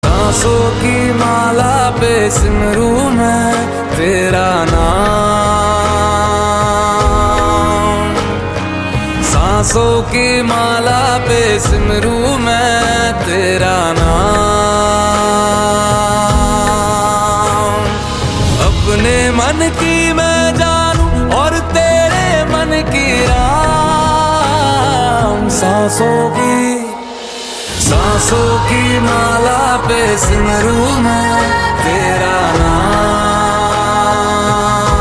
Popular Bollywood romantic devotional-melody ringtone